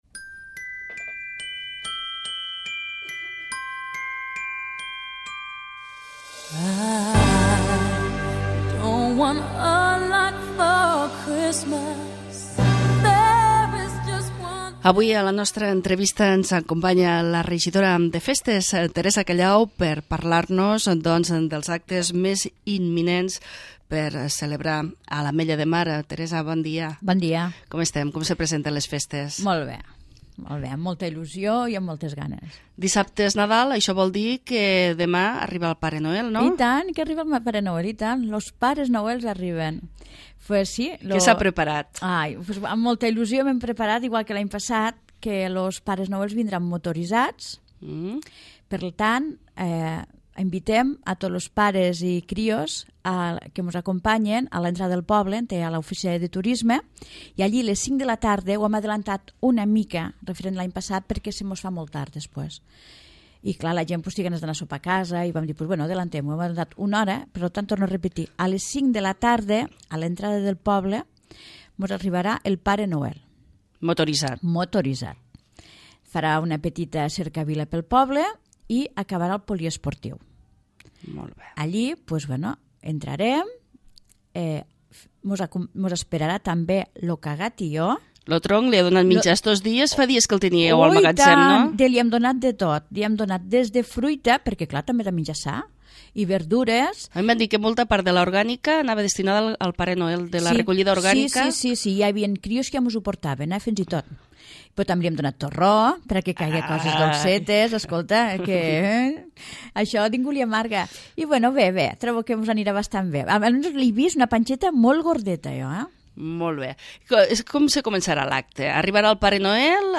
L'Entrevista
La regidora de Festes Teresa Callau ens ha explicat les activitats preparades per aquest Nadal , els guanyadors del 14è concurs escolar de postals de Nadal i els guanyadors del 28è concurs d'aparadors i façanes .